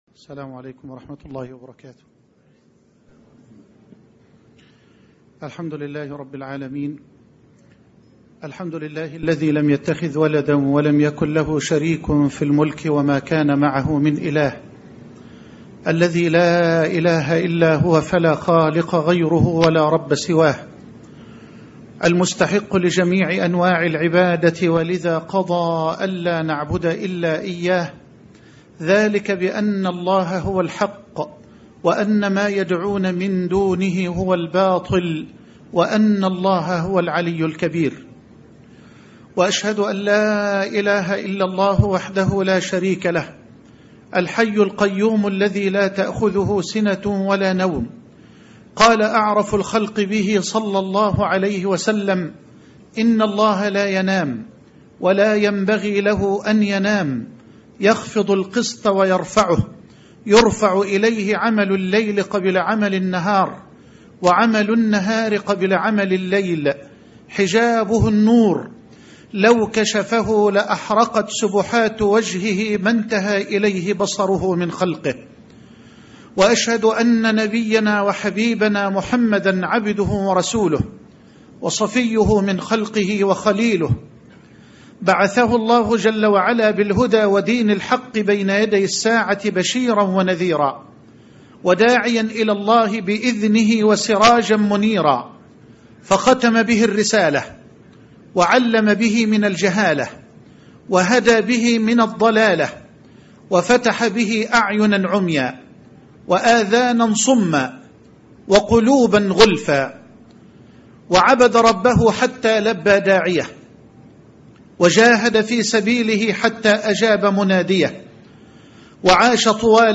شبكة المعرفة الإسلامية | الدروس | الطريق الأقوم |محمد حسان